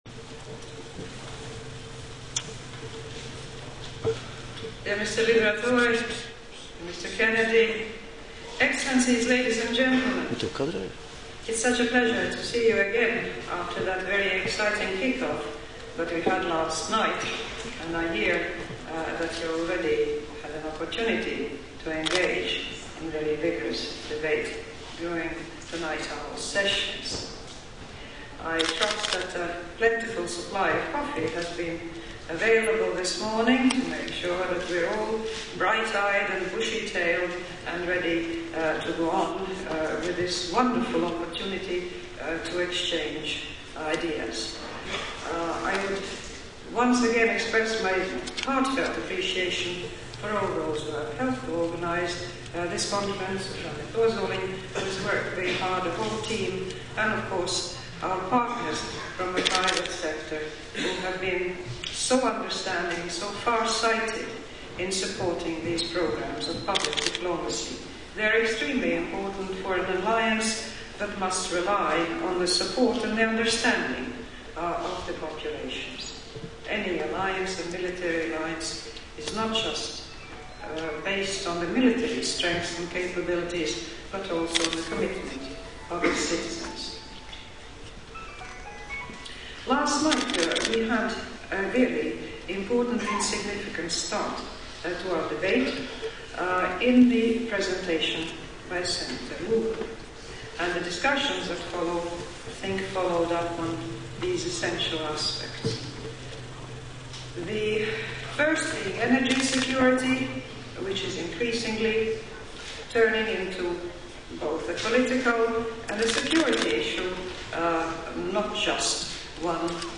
Audio: Valsts prezidentes uzruna Rīgas Konferencē 2006 Melngalvju namā